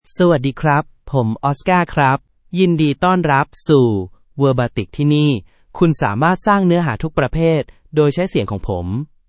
Oscar — Male Thai AI voice
Oscar is a male AI voice for Thai (Thailand).
Voice sample
Listen to Oscar's male Thai voice.
Male